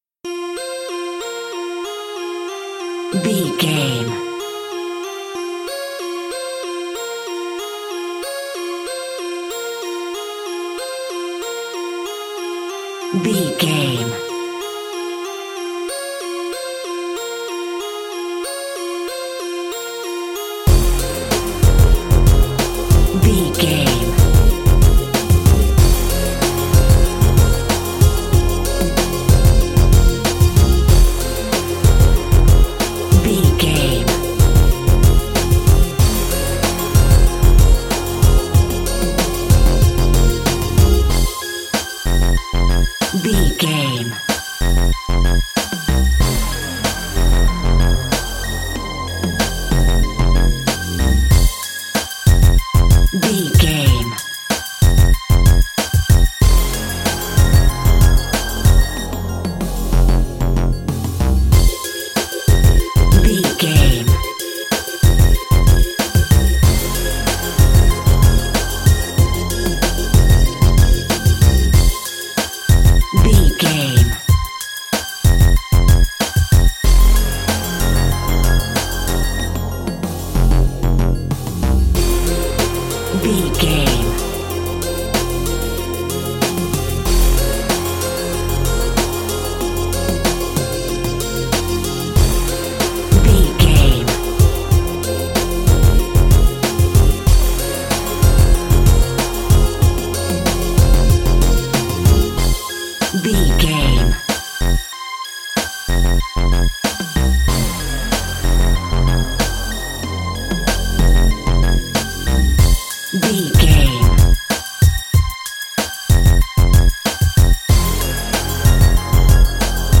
Aeolian/Minor
drum machine
synthesiser
electric piano